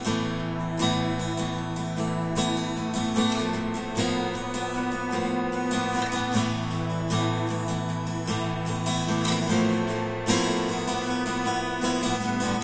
等到明天第三部分吉他
标签： 152 bpm Acoustic Loops Guitar Acoustic Loops 2.13 MB wav Key : Unknown
声道立体声